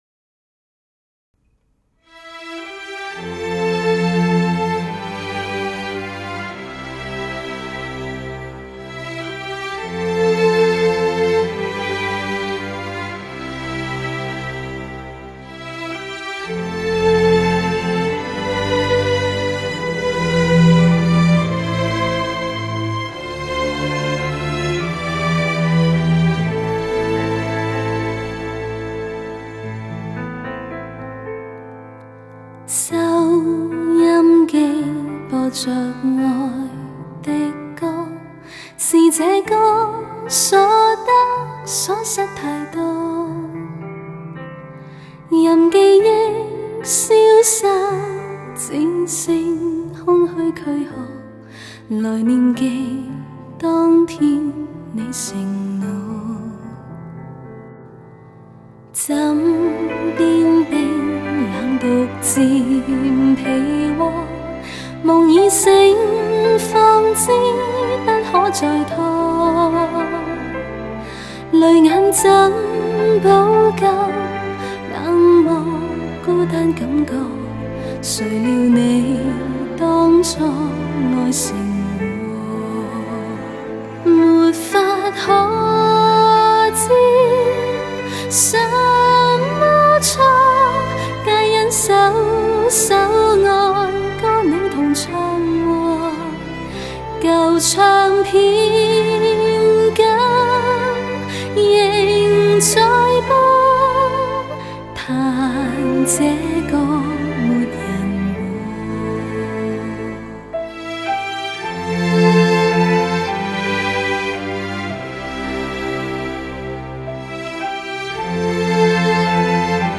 其青春、温柔与透明的嗓音如和风拨开迷雾，给人一种清新秀丽、充满时尚的崭新！
乐器的分隔度、定位良好，弦乐群华丽高贵，钢琴的形态和位置浮现，琴键的触音粒粒可闻。